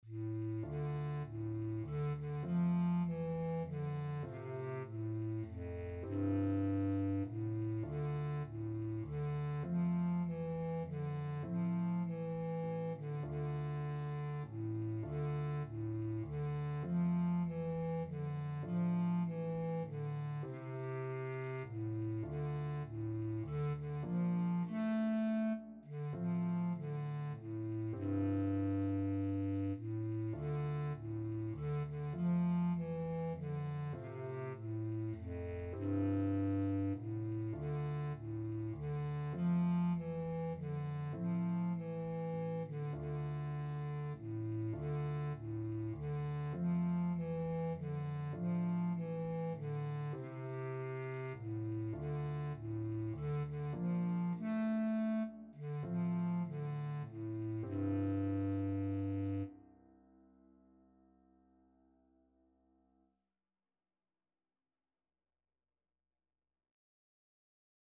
Bass Clarinet version
6/8 (View more 6/8 Music)
Traditional (View more Traditional Bass Clarinet Music)